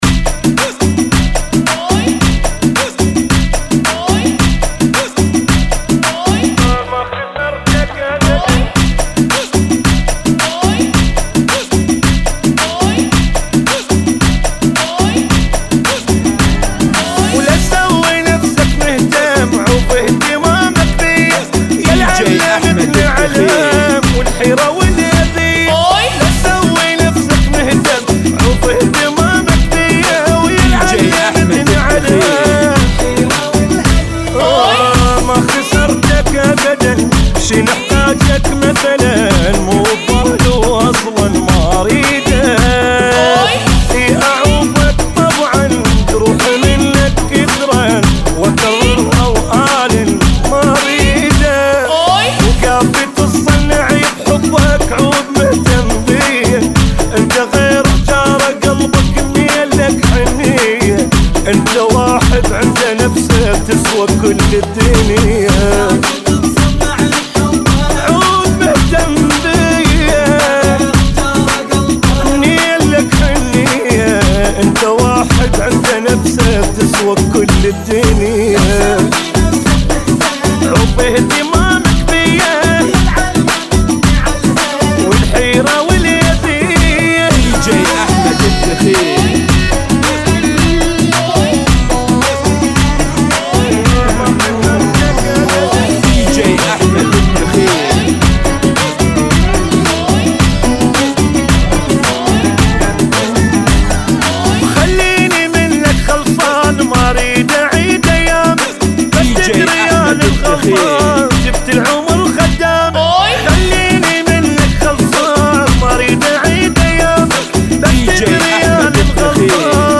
Funky Remix